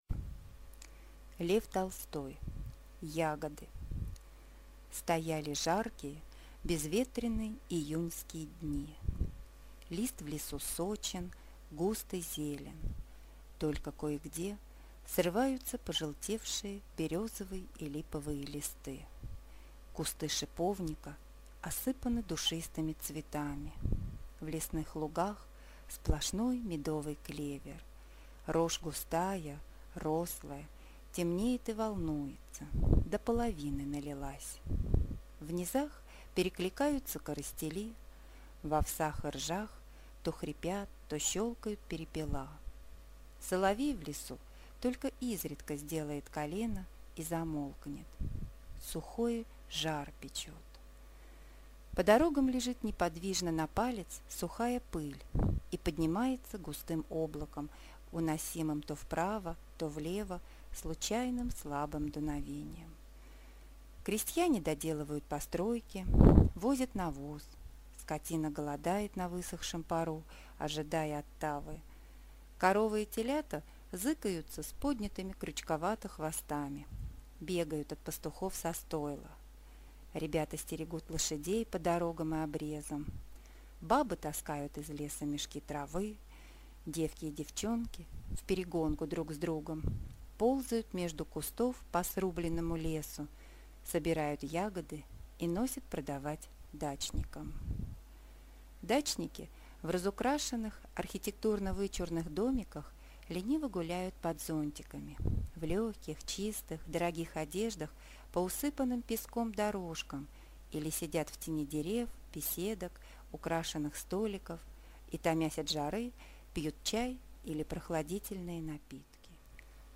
Аудиокнига Ягоды | Библиотека аудиокниг